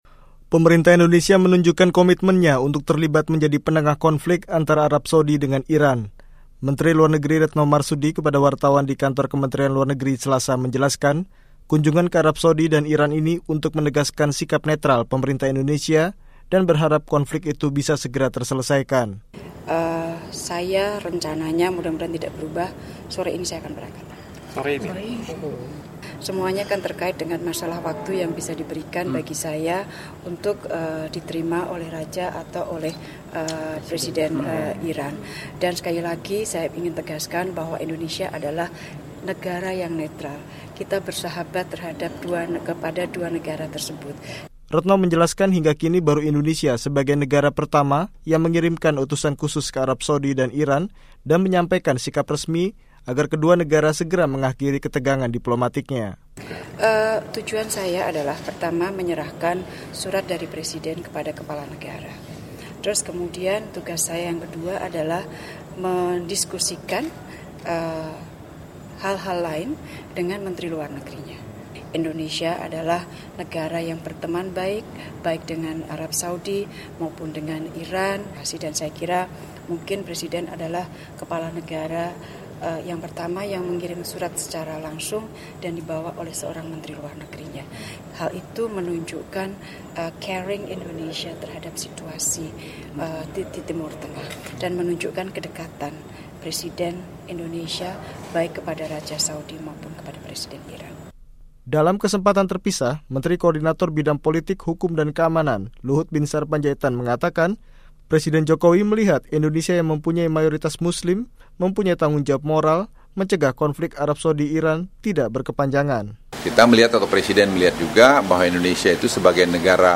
Menlu Retno Marsudi kepada wartawan di kantor Kementerian Luar Negeri Selasa (12/1) menjelaskan, kunjungan ke Arab Saudi dan Iran ini untuk menegaskan sikap netral Pemerintah Indonesia dan berharap konflik itu bisa segera terselesaikan.